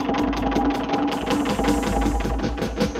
Index of /musicradar/rhythmic-inspiration-samples/80bpm
RI_DelayStack_80-01.wav